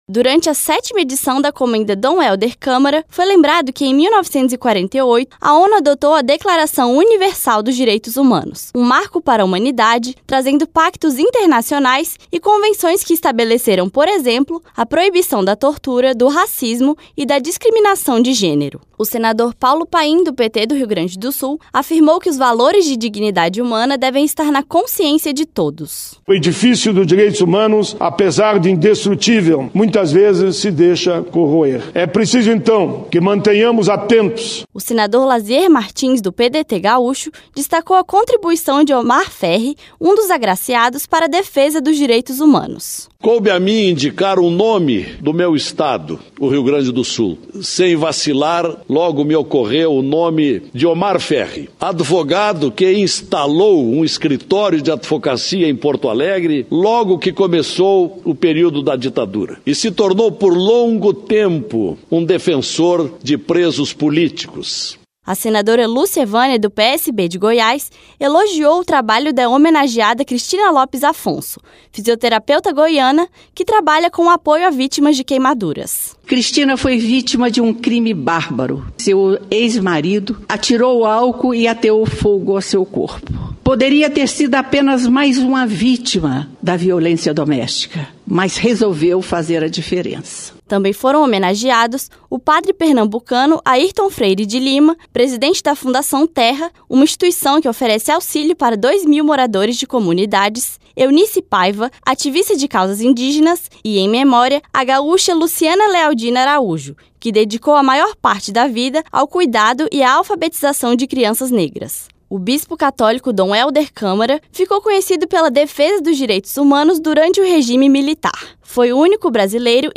Sessão Especial